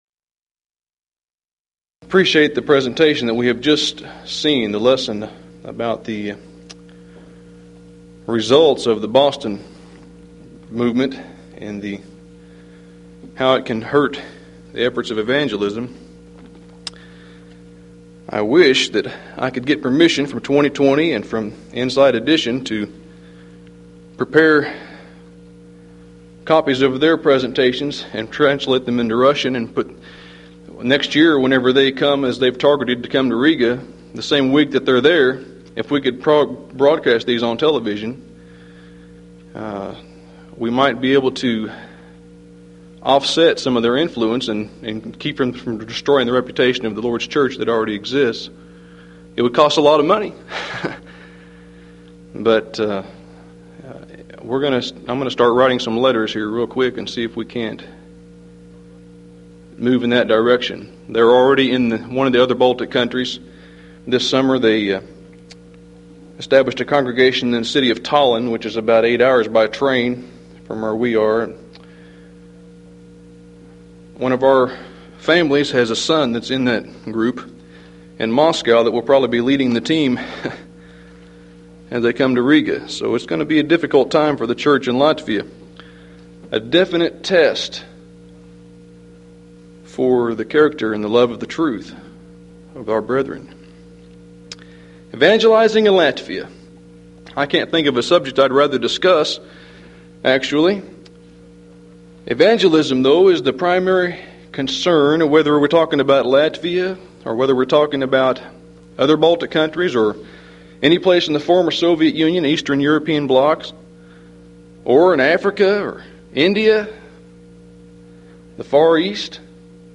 Event: 1994 Mid-West Lectures
lecture